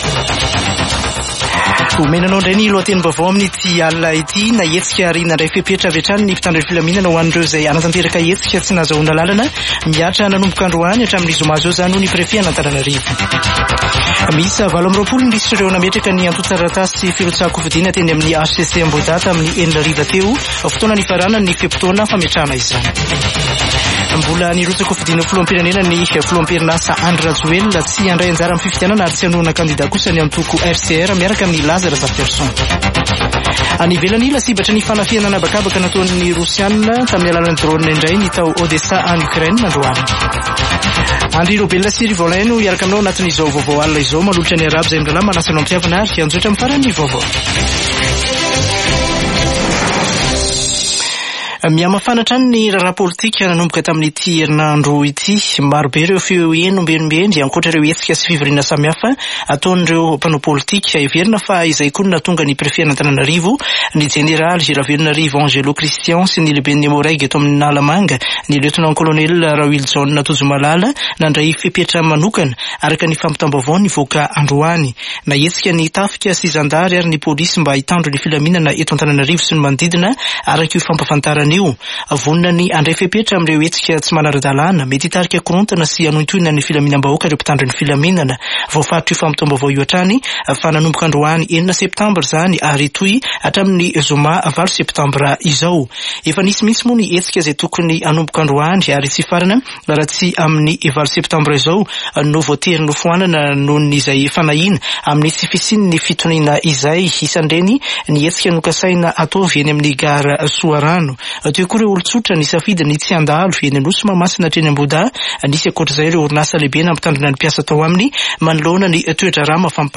[Vaovao hariva] Alarobia 6 septambra 2023